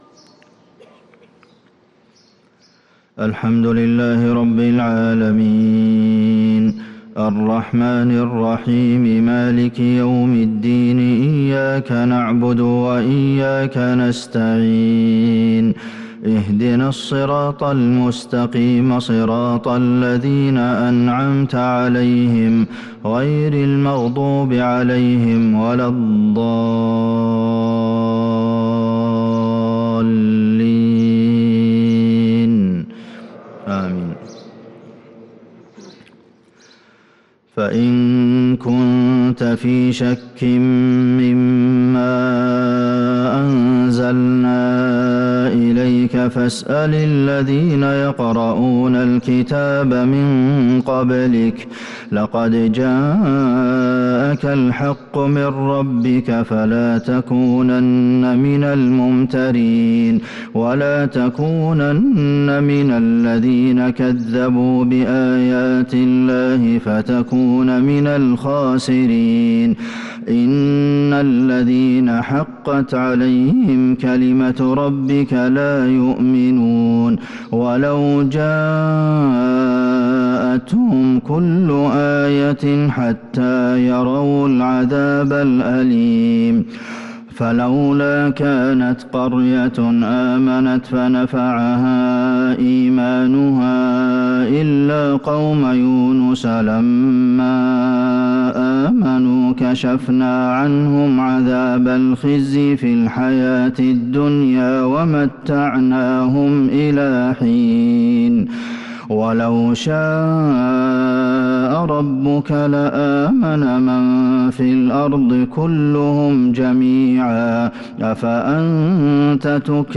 صلاة الفجر للقارئ عبدالمحسن القاسم 28 شعبان 1443 هـ
تِلَاوَات الْحَرَمَيْن .